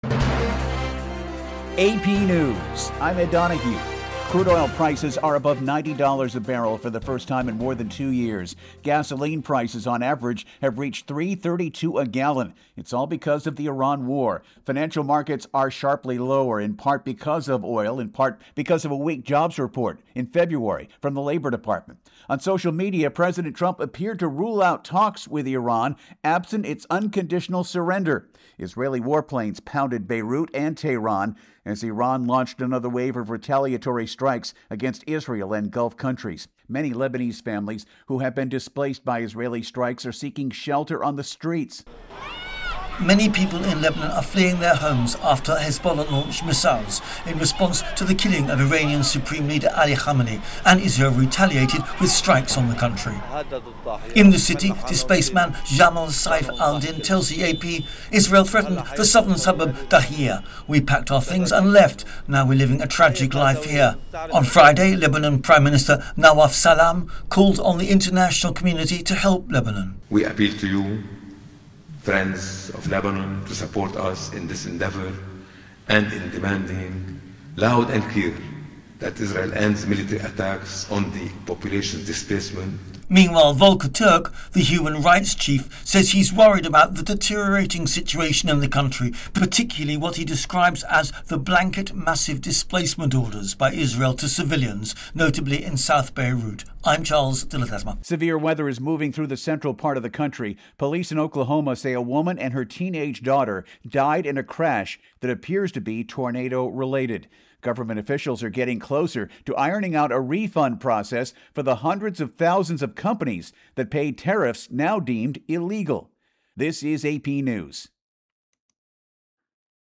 An episode by Headline News from The Associated Press